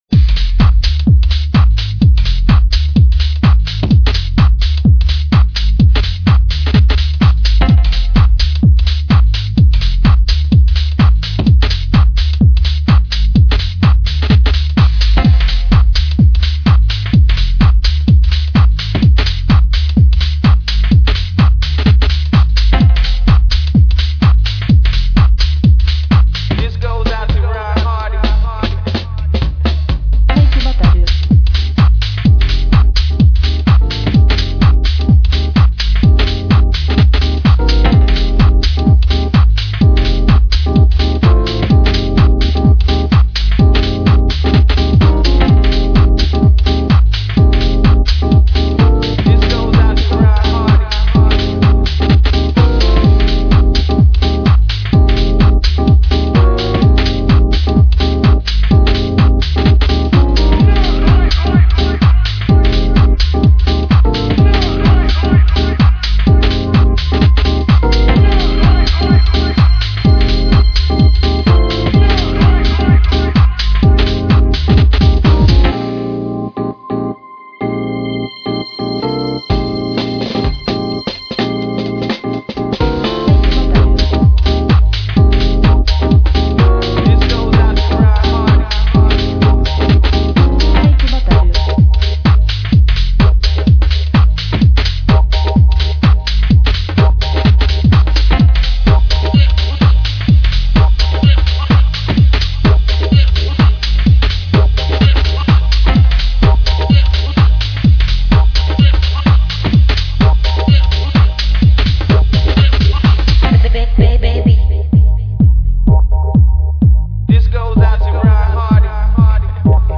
vibrant synths and buoyant house rhythms